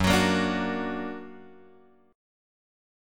F#+ chord {2 x 4 3 3 2} chord
Fsharp-Augmented-Fsharp-2,x,4,3,3,2.m4a